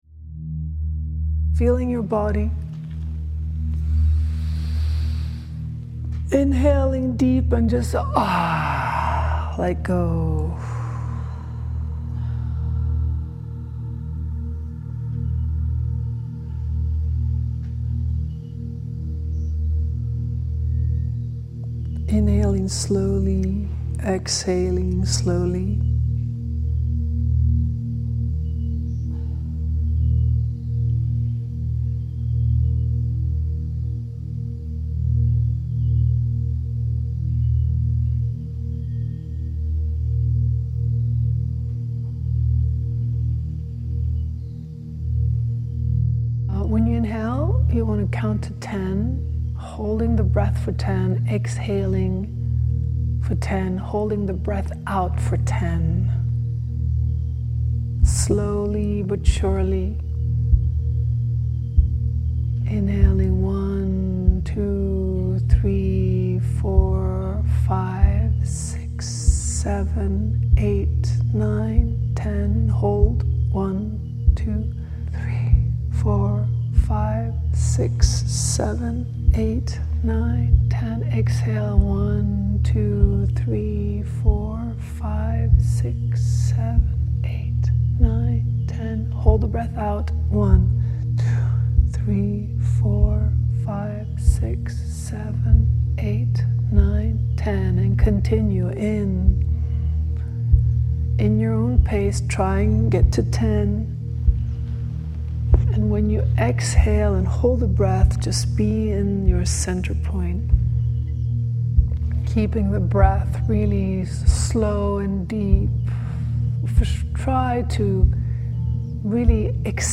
From there, you’ll be guided through a meditation that travels among the star constellations and into the magnetic frequency of Mother Earth.